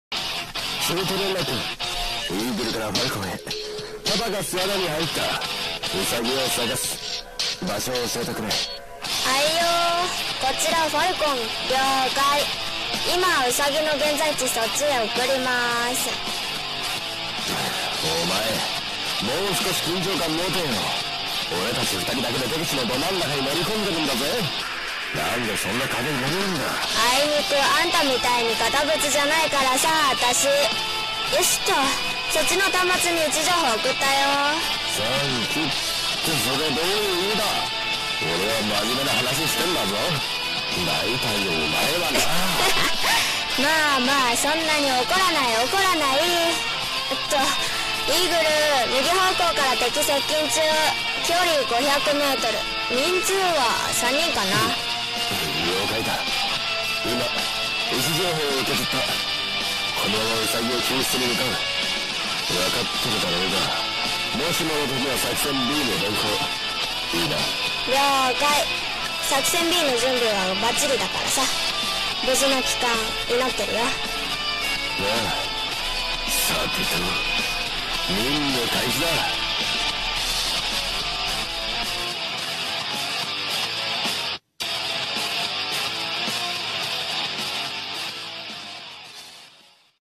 声劇台本｢二人のスパイ-救出任務-